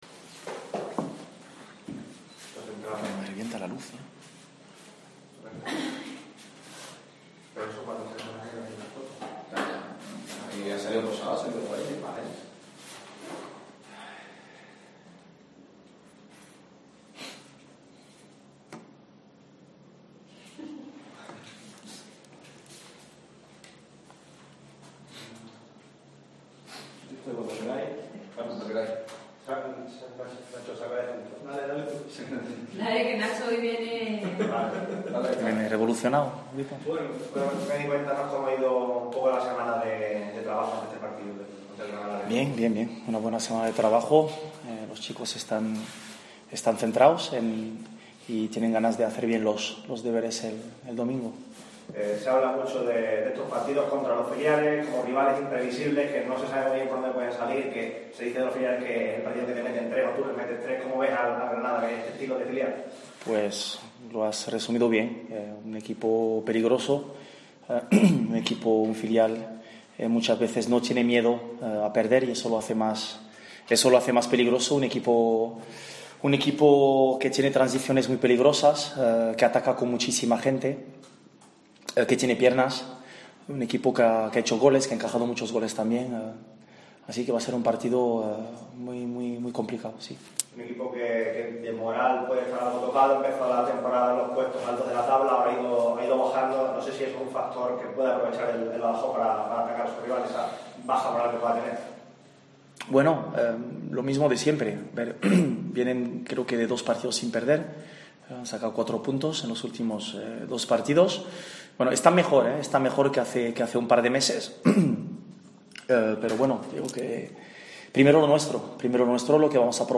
Rueda de Prensa